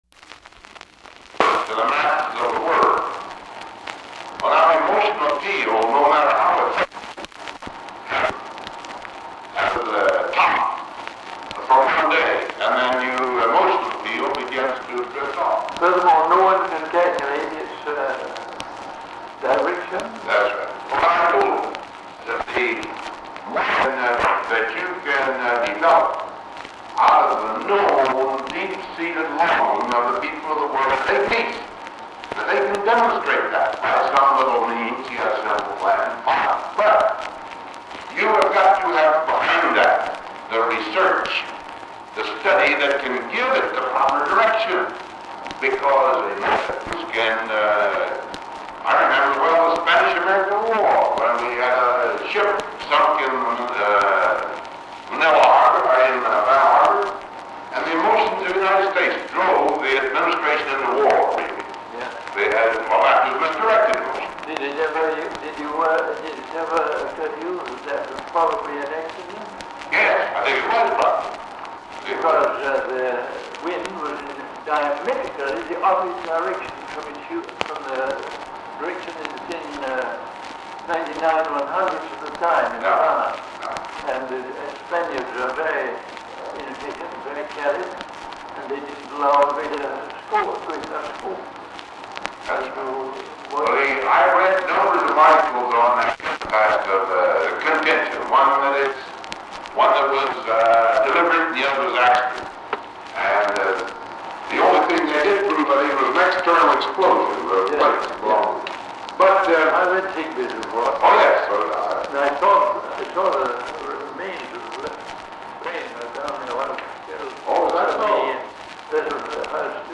The recording begins with the conversation already in progress. Eisenhower and Cabot are discussing the influence of public opinion on government policy.
Secret White House Tapes